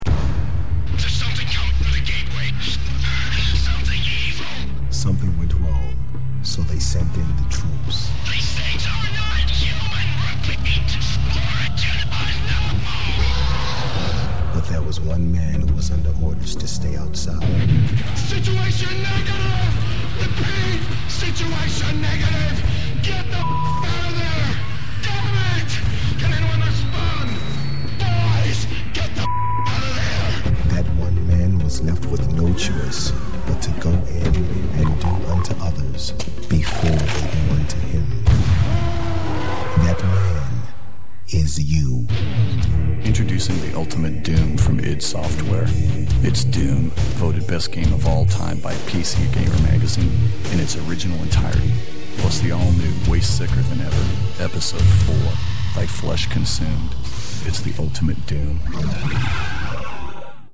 Ultimate DOOM Radio Ad